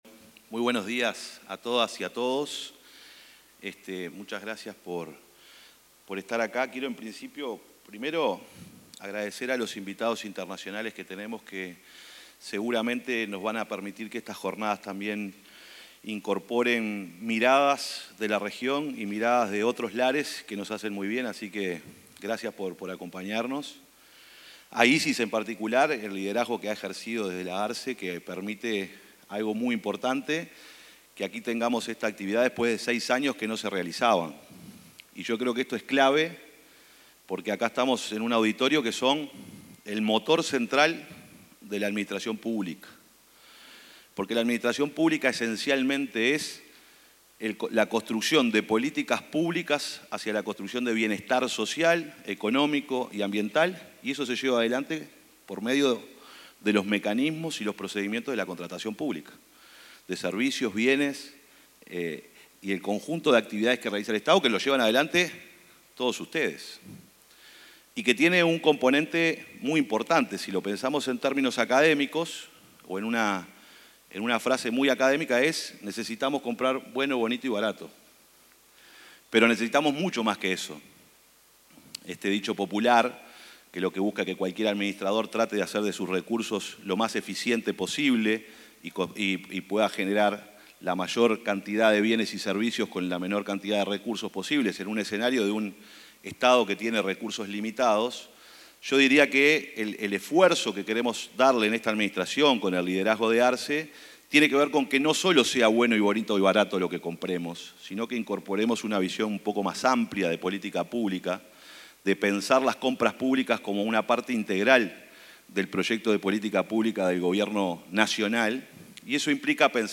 Palabras del secretario de Presidencia, Alejandro Sánchez, en VII Jornadas Técnicas de Compras y Contrataciones Estatales
Durante la apertura de las VII Jornadas Técnicas de Compras y Contrataciones Estatales, el secretario de la Presidencia, Alejandro Sánchez, subrayó el rol estratégico de las compras públicas para mejorar la gestión estatal. Con la actividad, organizada por la Agencia Reguladora de Compras Estatales, se busca generar un espacio de encuentro e intercambio sobre los avances y desafíos del sistema de contrataciones.